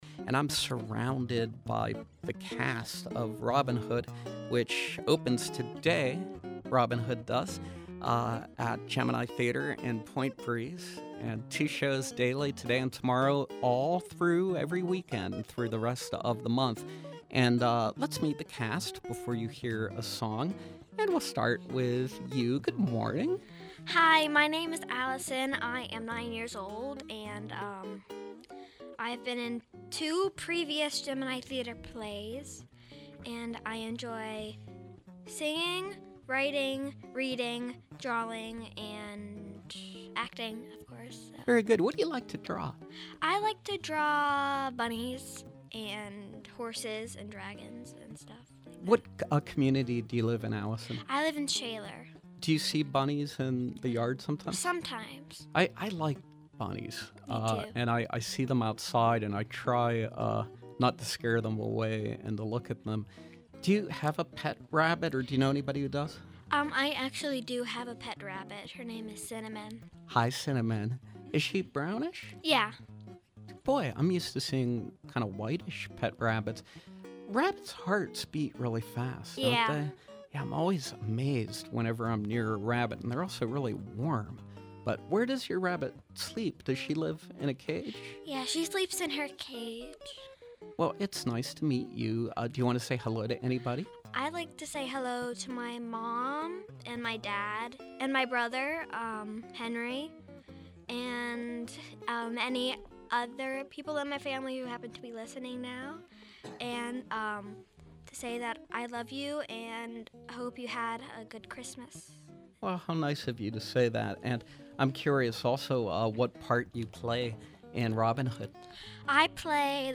Gemini Theater produces original, interactive, children’s musicals which focus on artistic, cultural and educational themes. This week we welcome members of the cast of ‘Robin Hood’ as they preview their production of this classic story.